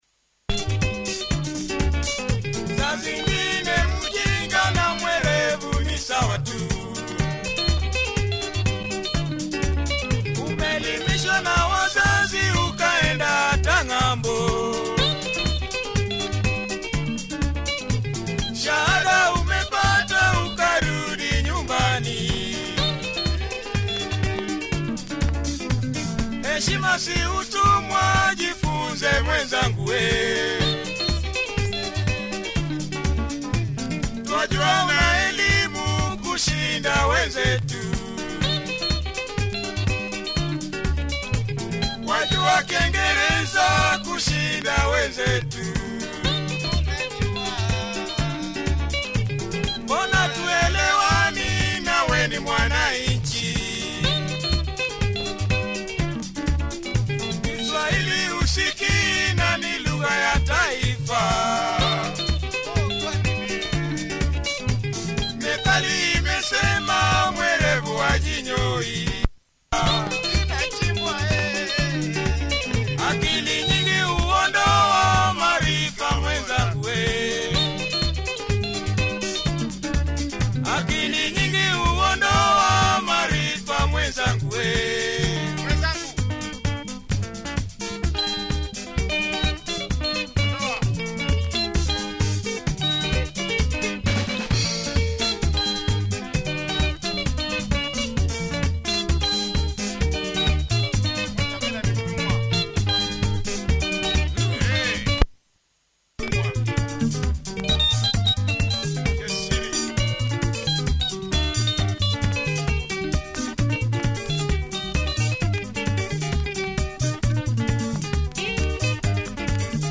Nice luo benga by thisartist